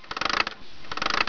corda.wav